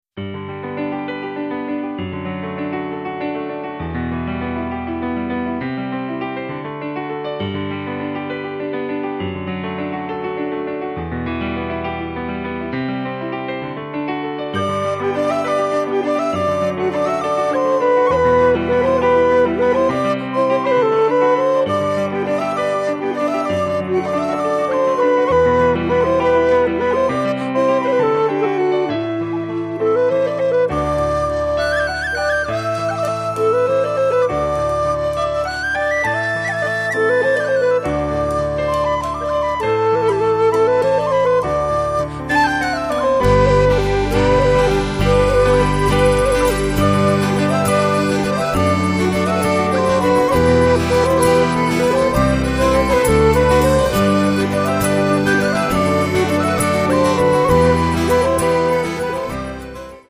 Il Gruppo di musica folk & celtica